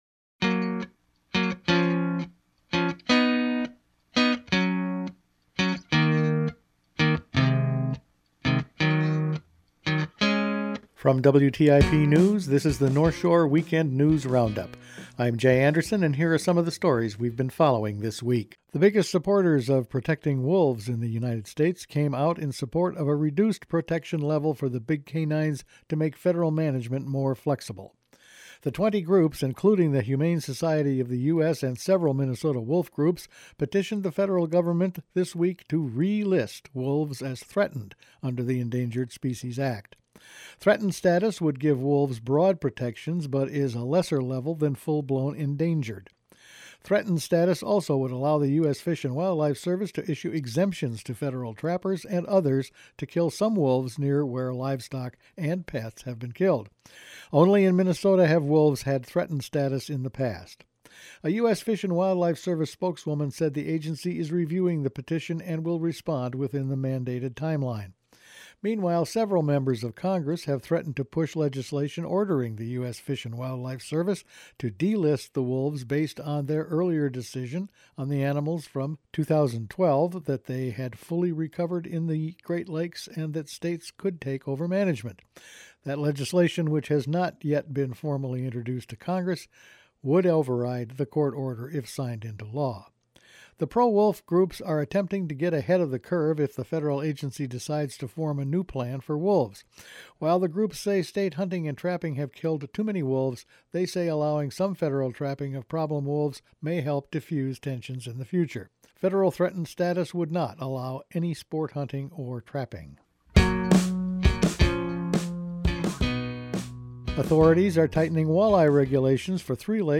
Weekend News Roundup for January 31